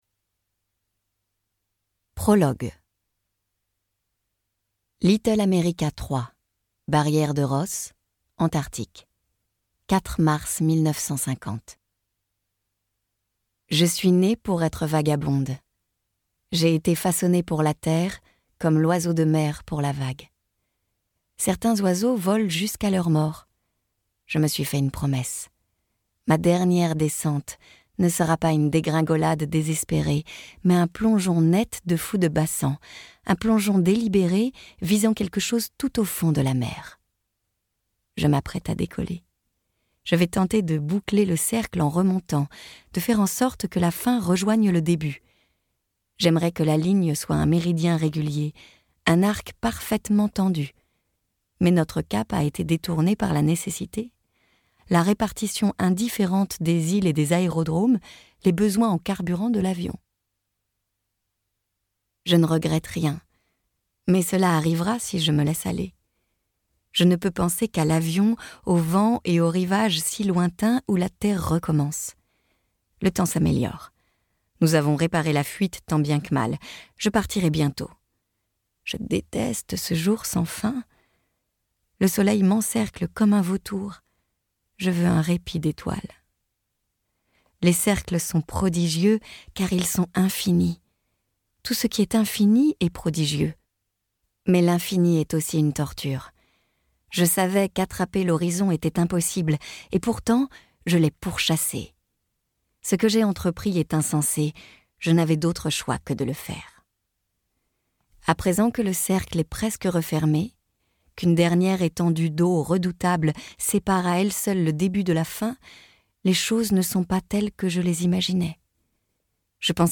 Extrait gratuit
Une épopée féministe, portée par un duo complémentaire de comédiennes !